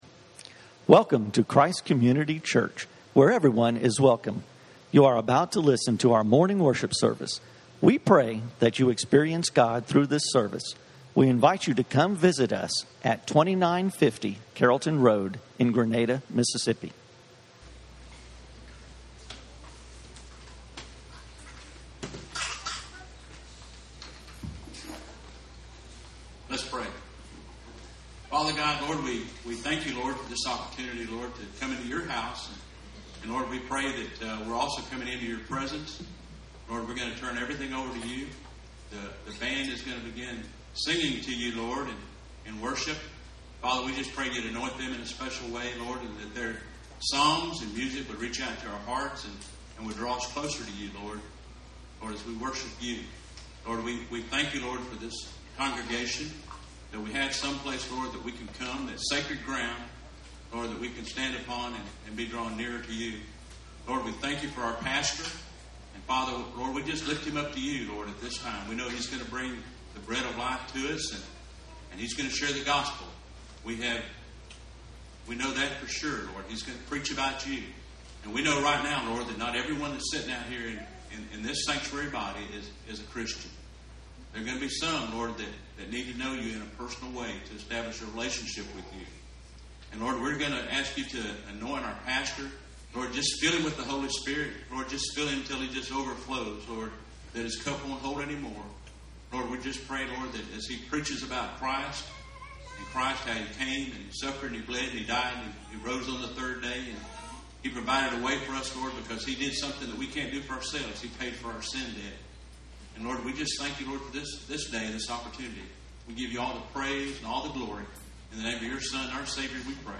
The Church of God for Real Love - Messages from Christ Community Church.